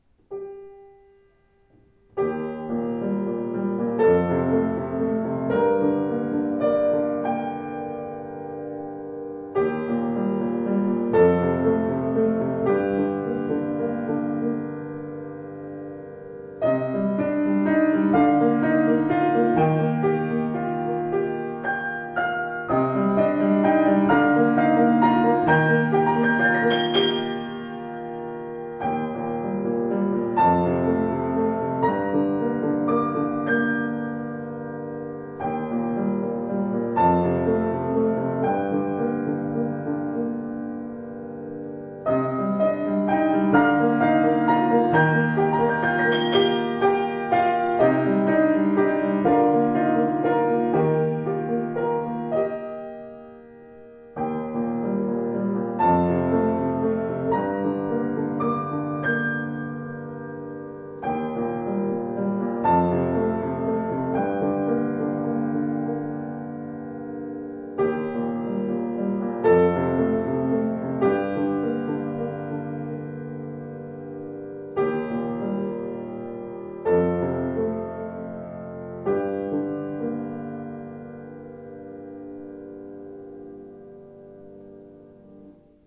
Klangproben eines anderen, baugleichen Steinway Z: